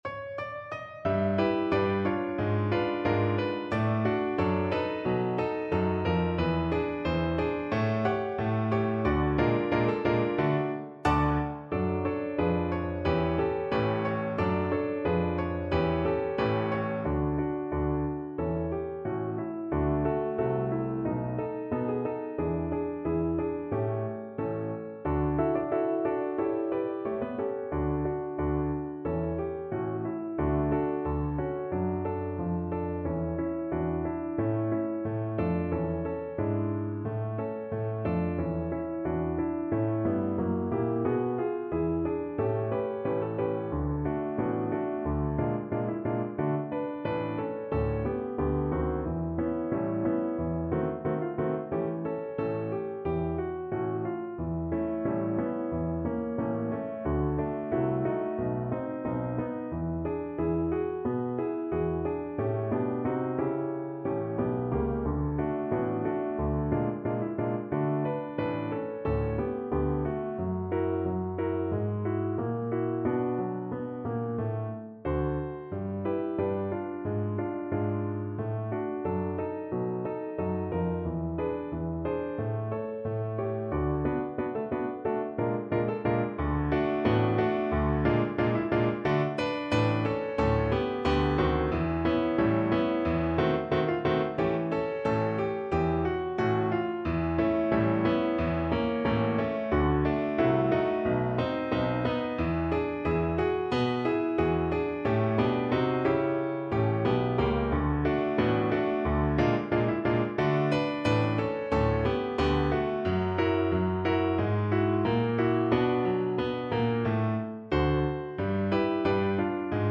~ = 180 Moderato
Pop (View more Pop Violin Music)